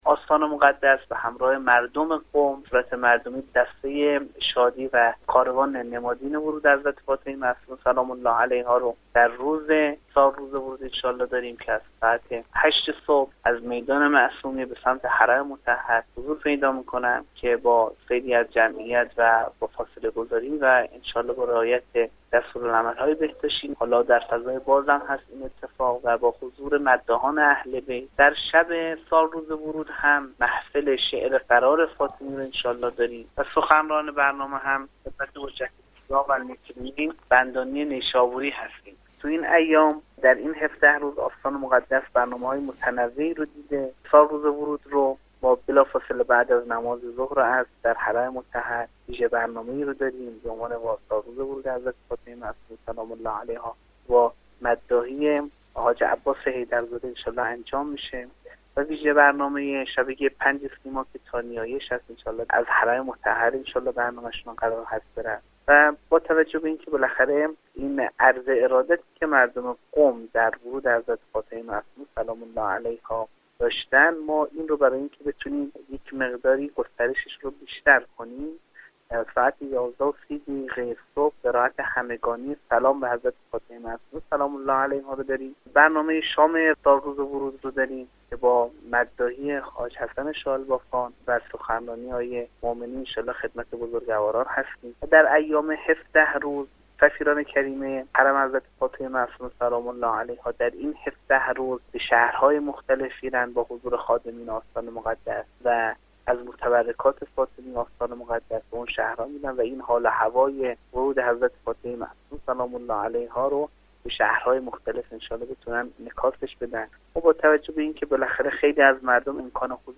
گفتگوی ویژه خبری رادیو